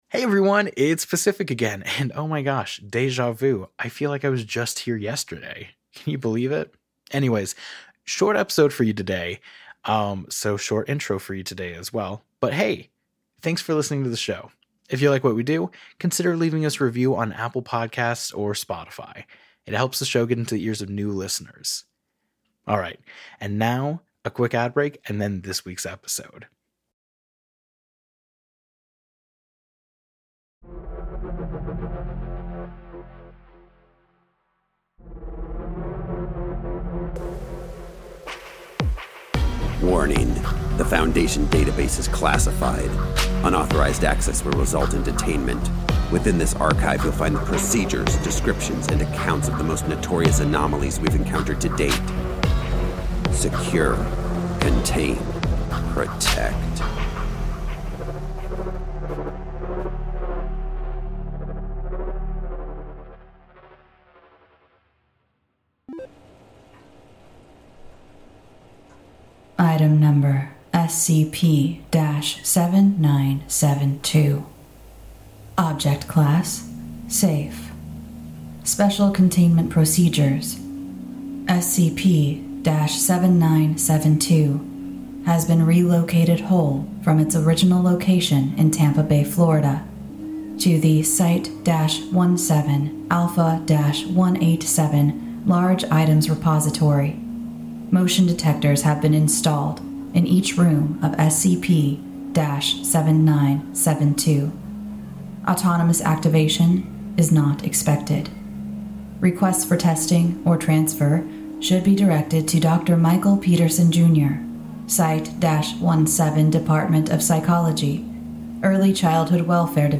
Arts, Society & Culture, Tv & Film, Drama, Fiction, Science Fiction